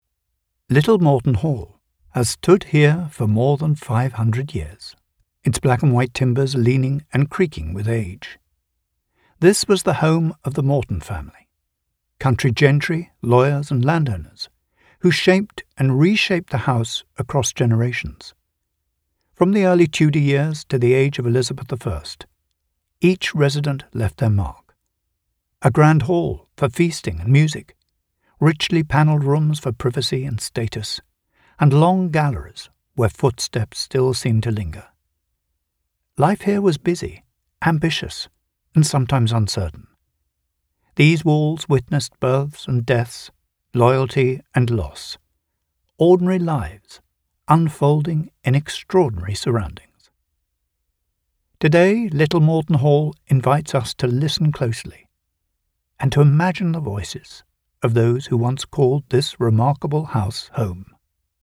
Male
English (British)
Adult (30-50), Older Sound (50+)
Documentary